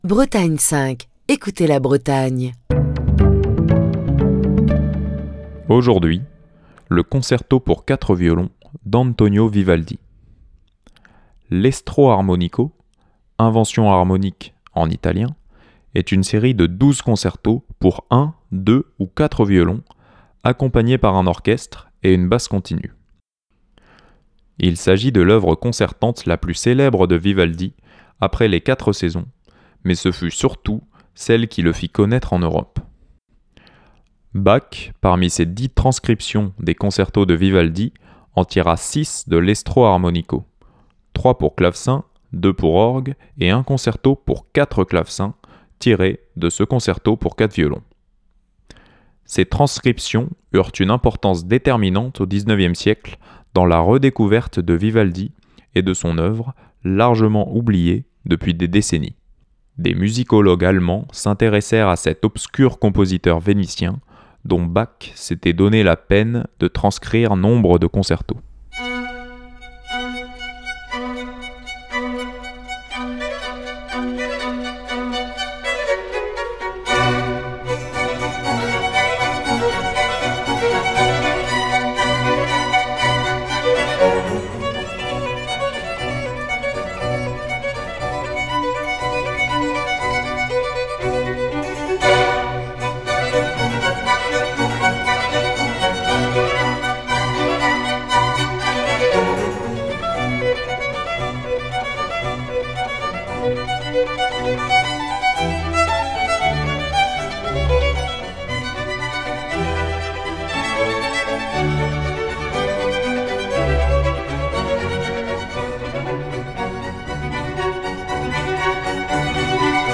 Fil d'Ariane Accueil Les podcasts Antonio Vivaldi - Le concerto pour quatre violons Antonio Vivaldi - Le concerto pour quatre violons Émission du 13 décembre 2023. L'estro armonico - l'invention harmonique, en italien - est une série de douze concertos pour un, deux, ou quatre violons, accompagné par un orchestre, et une basse continue.